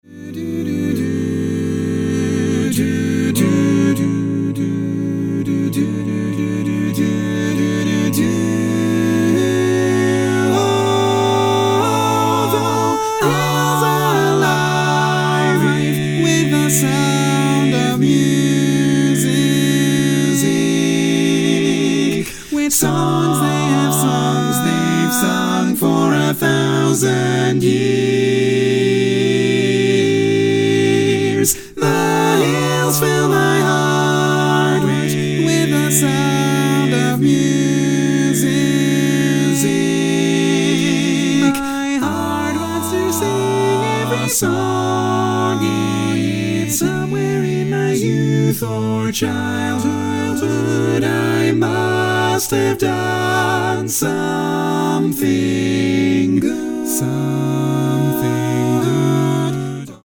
Full mix
Category: Male